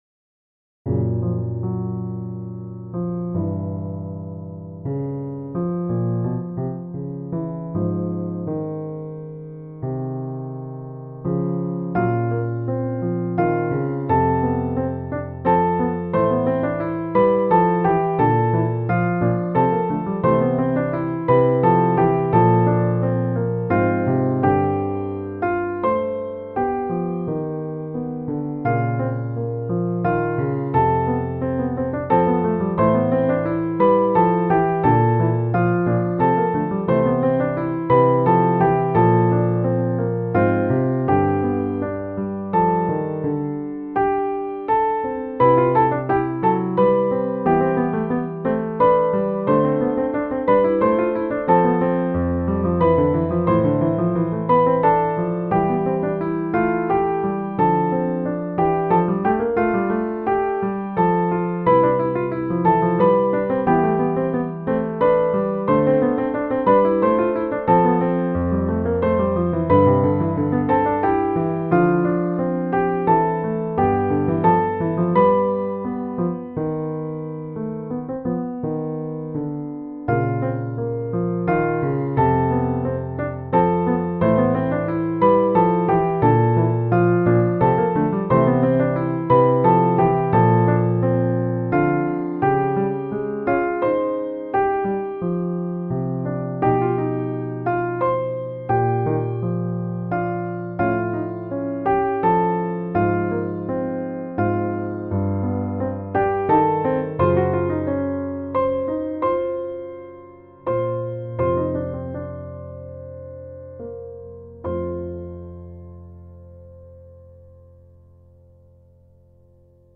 Piano duet
Piano duet 1st part easy